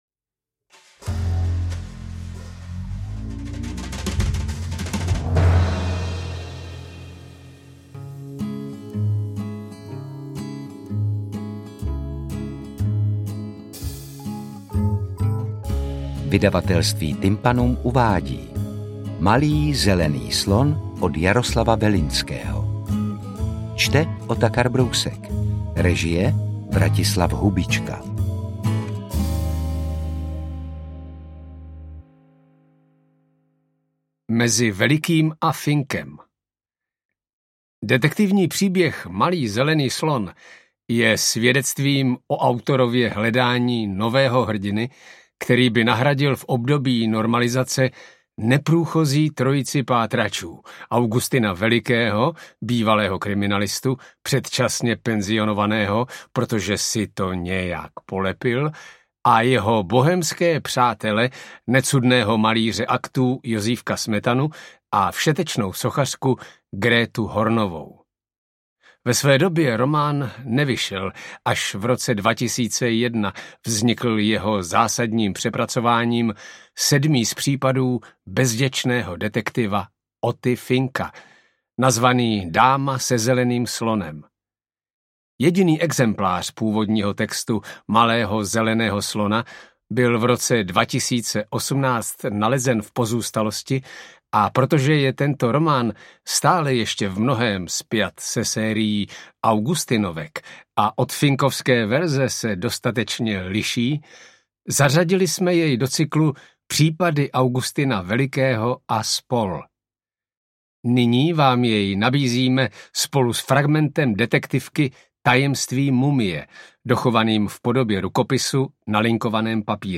Interpret:  Otakar Brousek